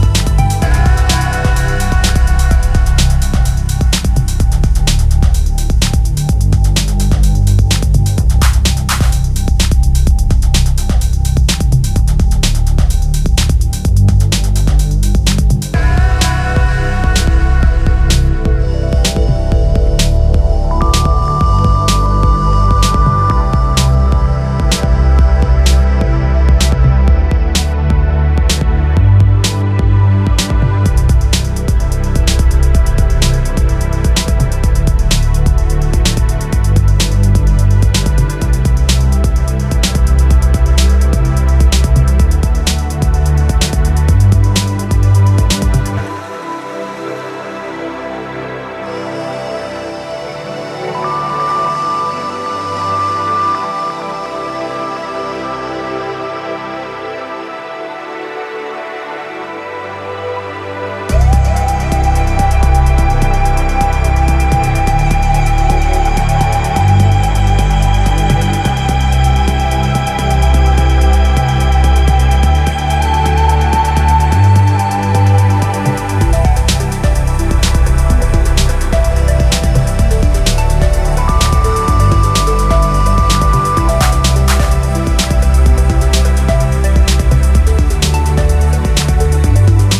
ジャンル(スタイル) DEEP HOUSE / TECH HOUSE